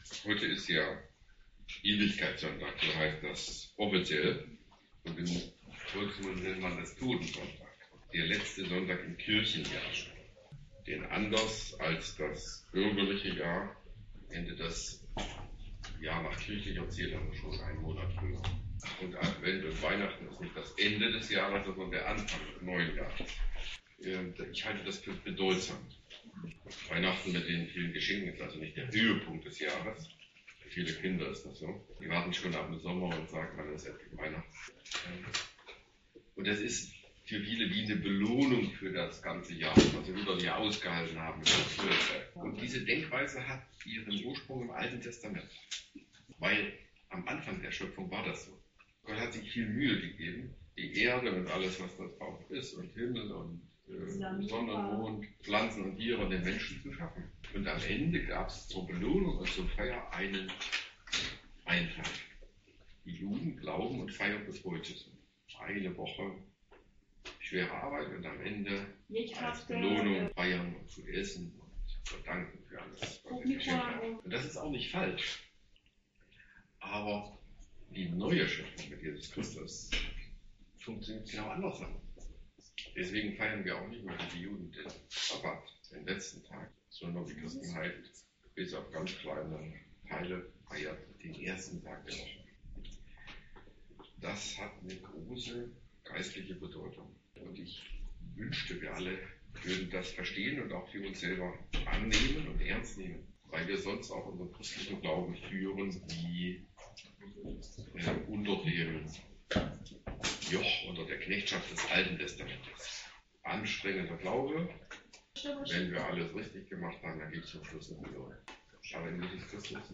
Jesaja 65,17 – 25 | Predigt zum Thema: Ewigkeitssonntag - Europäische Missionsgemeinschaft